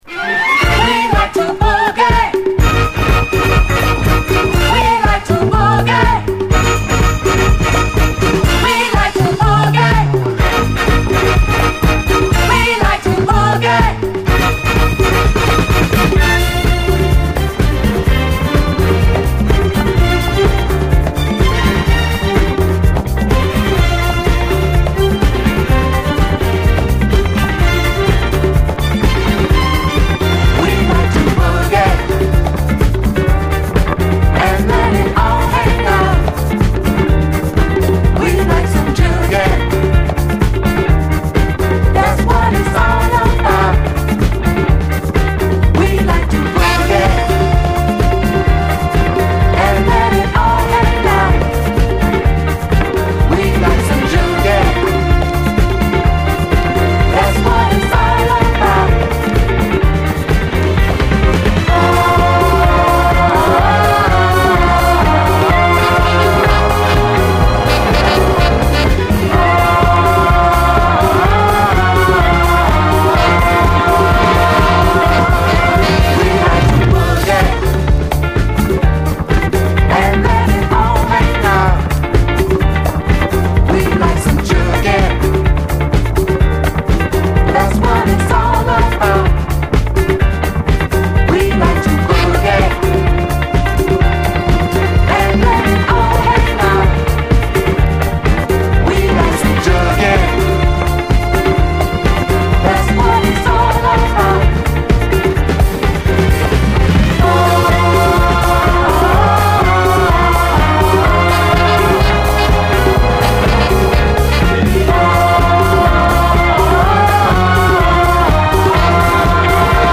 SOUL, 70's～ SOUL, DISCO
ラテンっぽいエレガントなサビメロもよい！